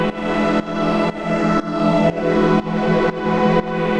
Index of /musicradar/sidechained-samples/120bpm
GnS_Pad-dbx1:4_120-E.wav